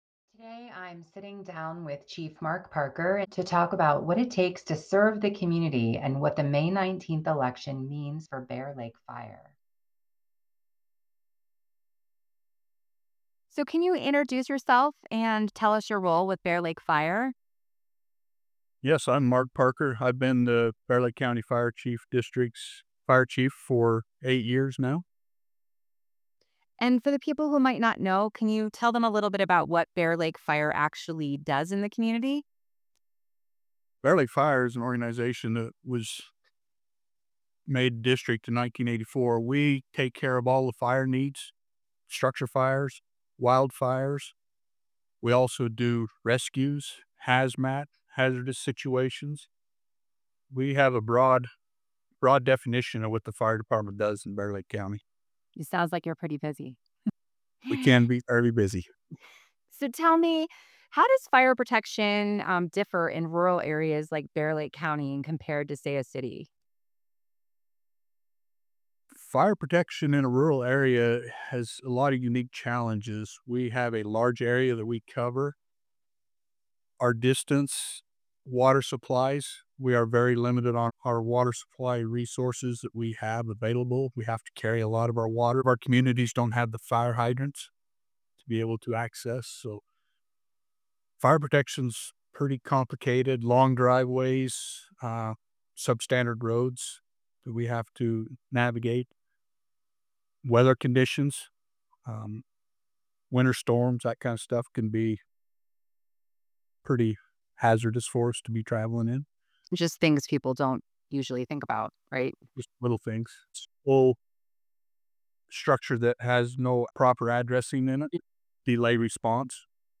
Bear Lake Fire Interview 3.31.26